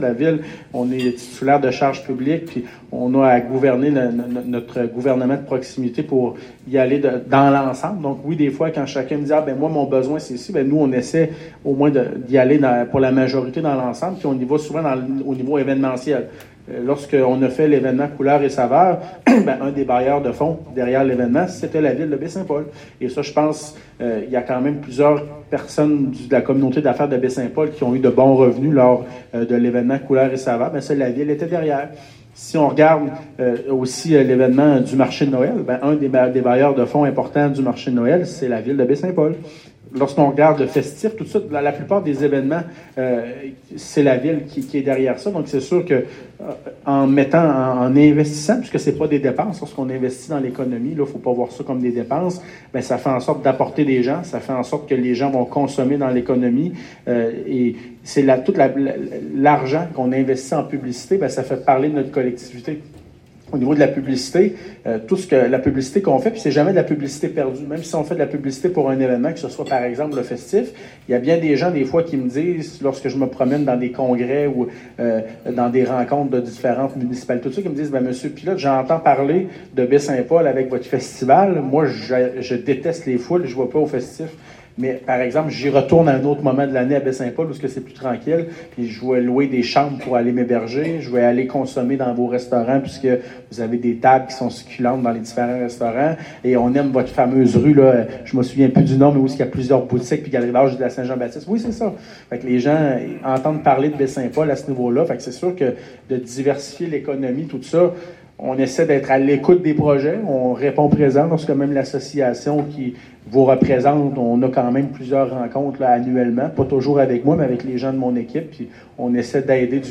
Une trentaine de convives s’étaient rassemblés dans l’ambiance chaleureuse et pittoresque de l’auberge de la rue Saint-Jean-Baptiste pour une séance de questions/réponses sans filtre.